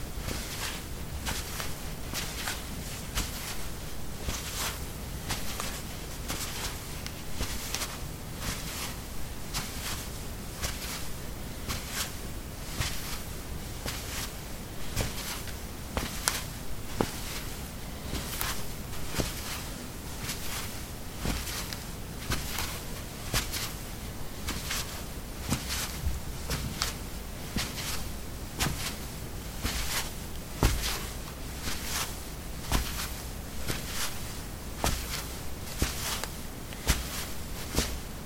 脚踏实地的瓷砖 " 陶瓷01A赤脚走路
描述：在瓷砖上行走：赤脚。在房子的浴室里用ZOOM H2记录，用Audacity标准化。